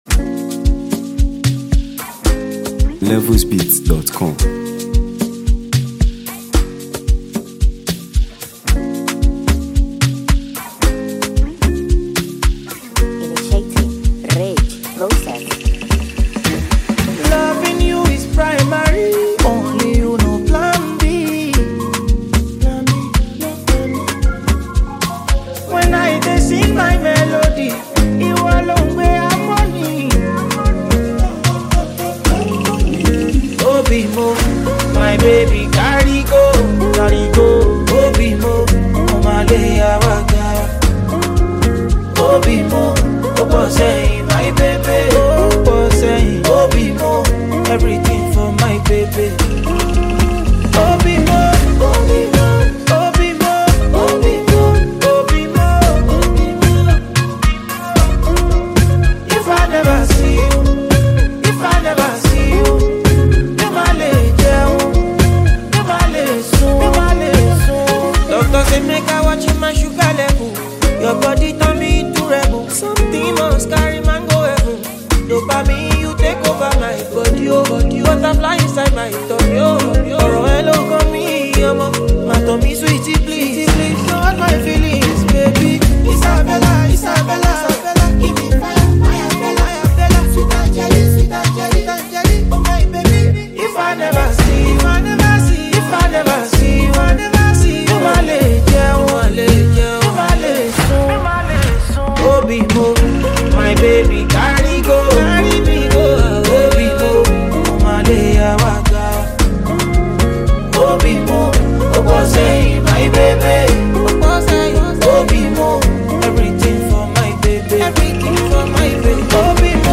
smooth vocals layered over rich melodies
making it a standout for lovers of soulful Afrobeats.
if you enjoy meaningful, soothing, and well-crafted music